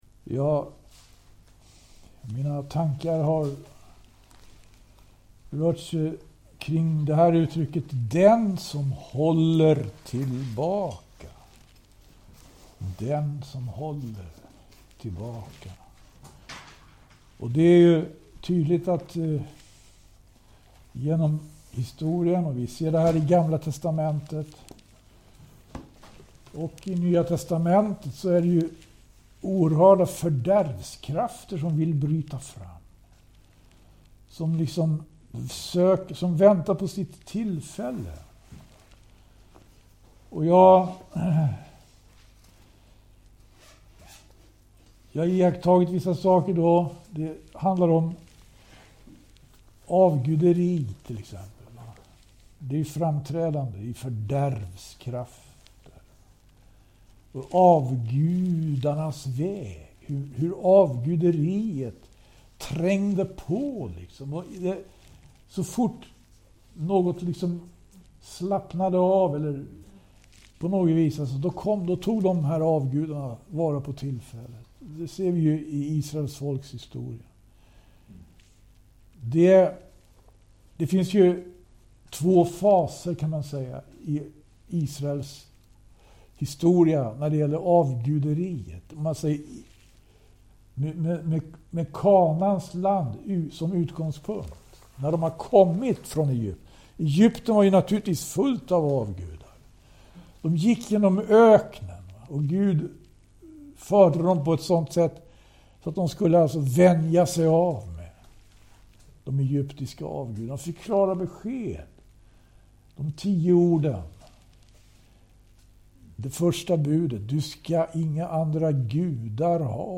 Bibelstudium
i Skälby